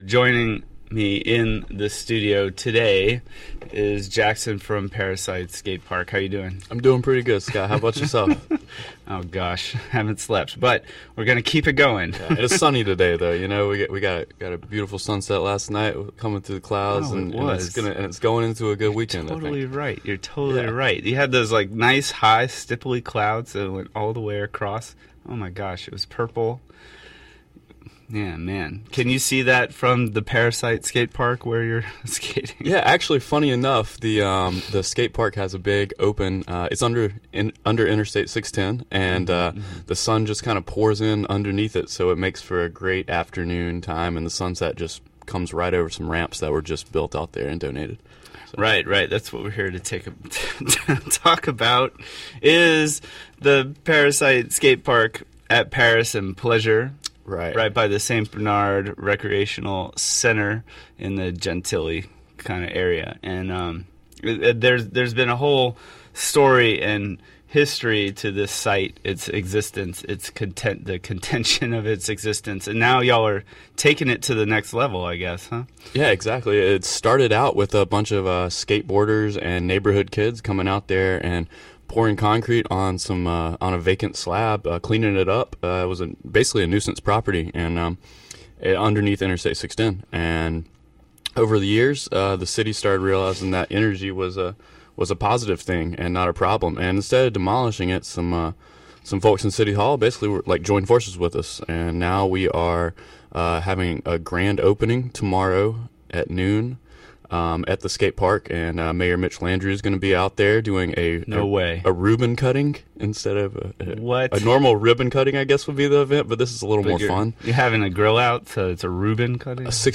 in the extended interview